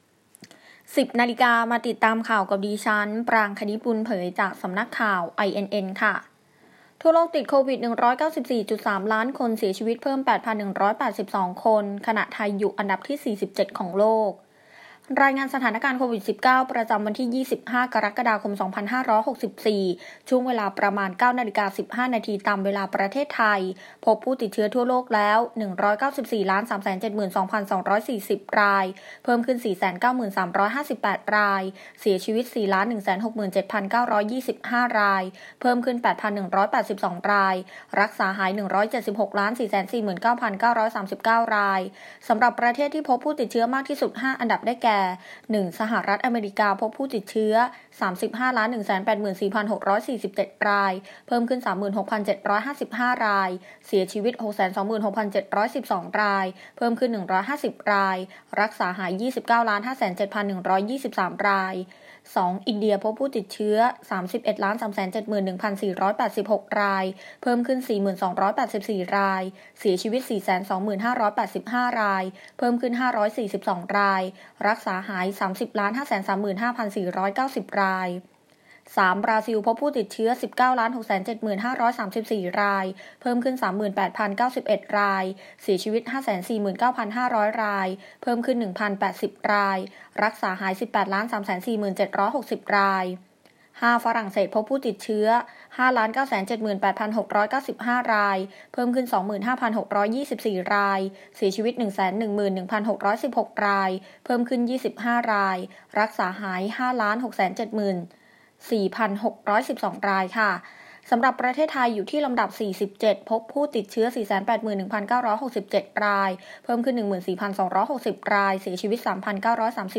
ข่าวต้นชั่วโมง 10.00 น.